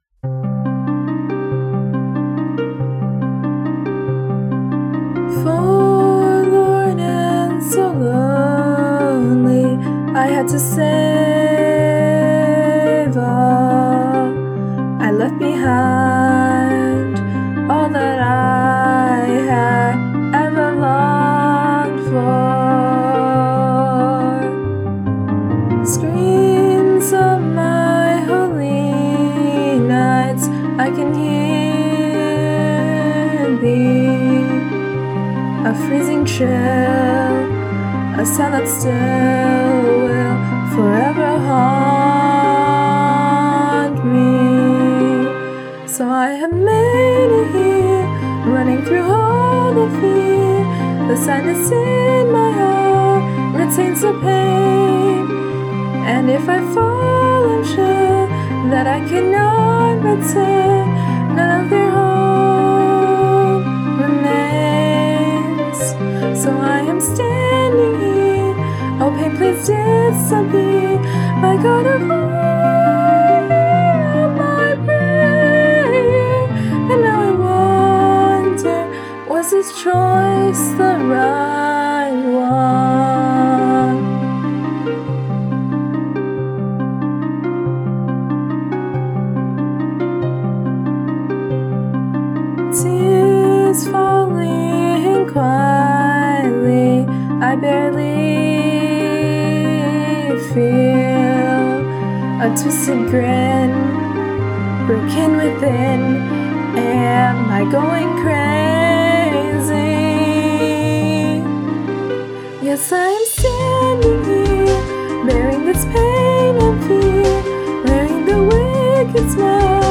We finally finished singing covers for those songs!
Staff Rendition 2: